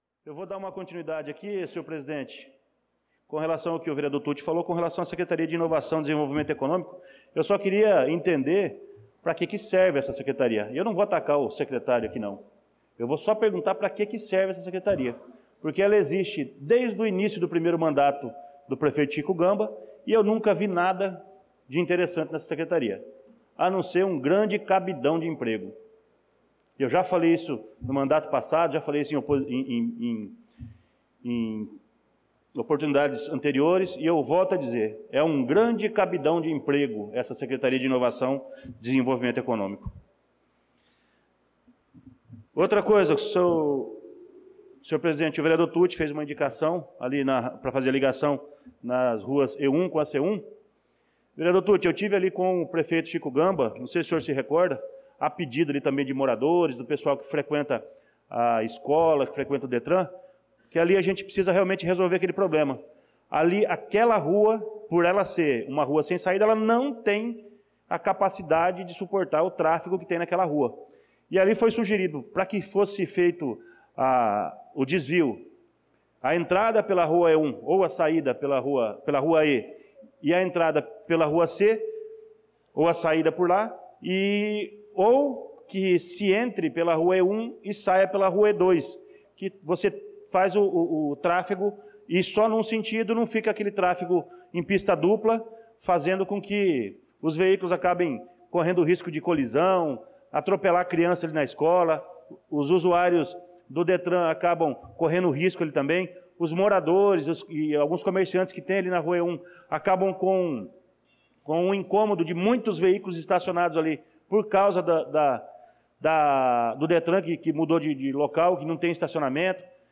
Pronunciamento do vereador Luciano Silva na Sessão Ordinária do dia 23/06/2025.